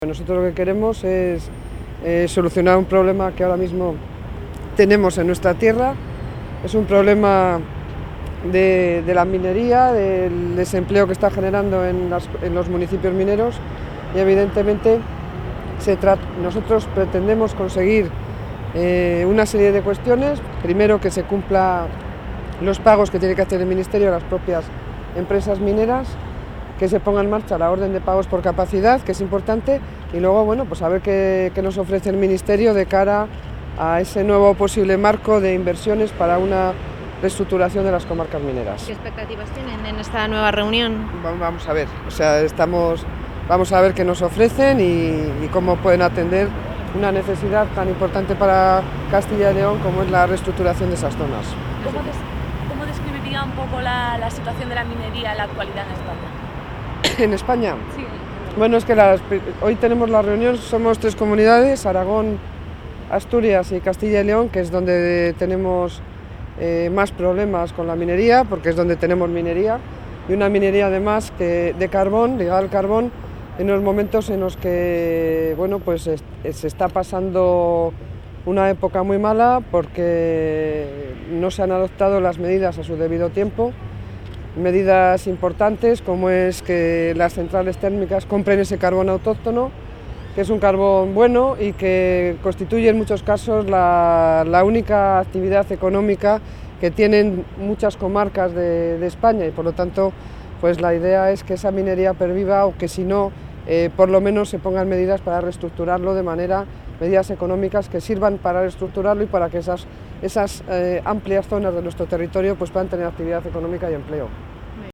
Declaraciones de la consejera de Economía y Hacienda previas a la reunión.